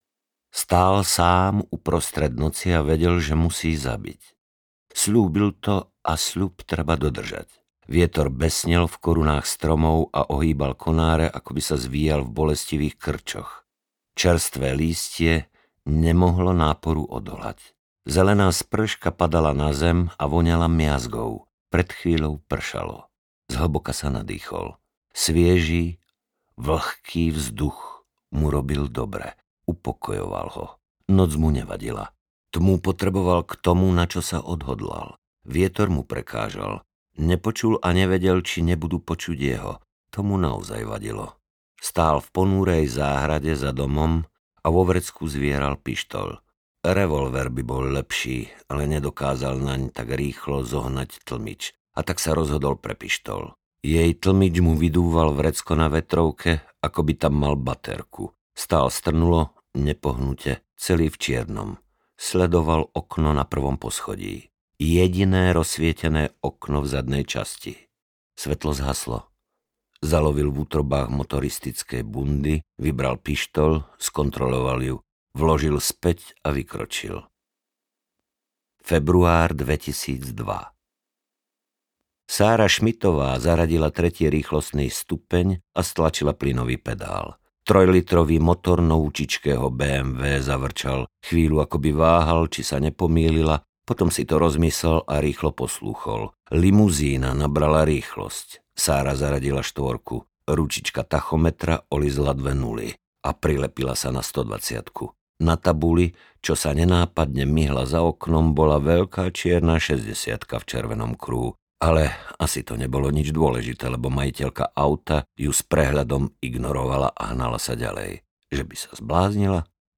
Sára audiokniha
Ukázka z knihy